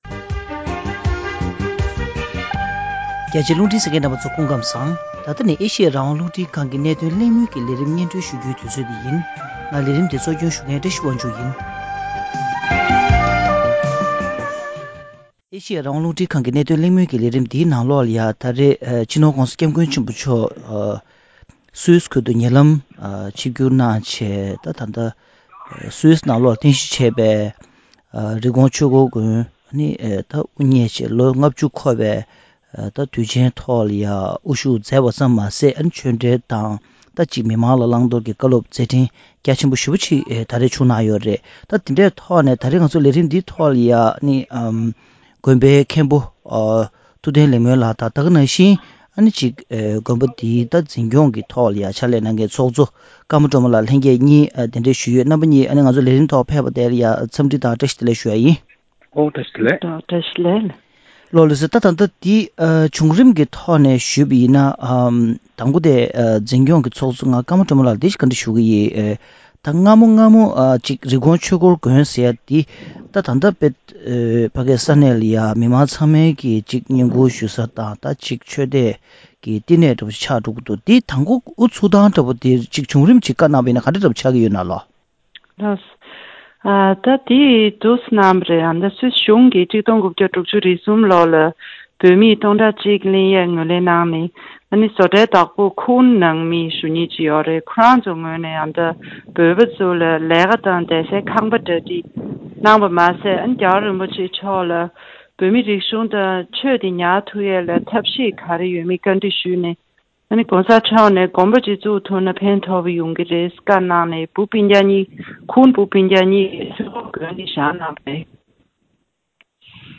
རི་ཀོན་ཆོས་འཁོར་དགོན་དབུ་བརྙེས་ནས་ལོ་ངོ་ ༥༠ འཁོར་བའི་དུས་དྲན་འབྲེལ་དགོན་པའི་ཆགས་རབས་དང་ལས་དོན་གནང་ཕྱོགས་ཐད་གླེང་མོལ།